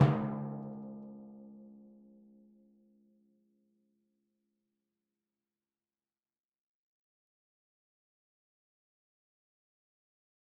Timpani3_Hit_v4_rr2_Sum.mp3